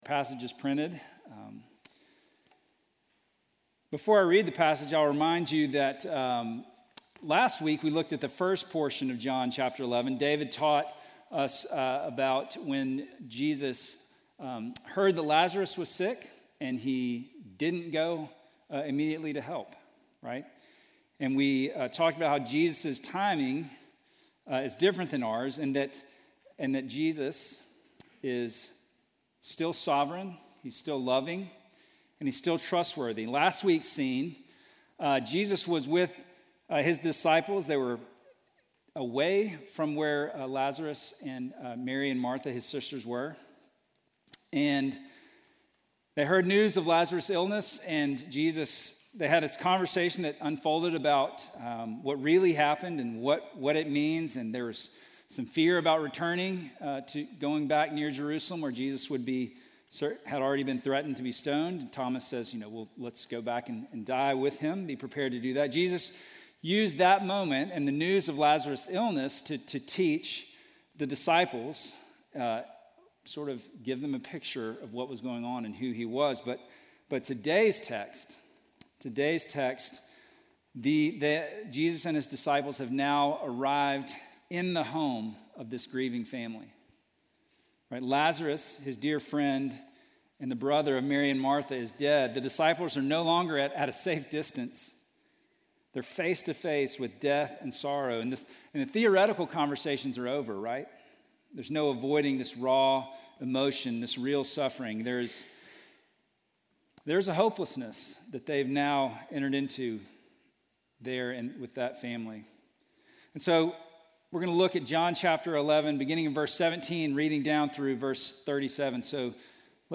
Sermon from November 16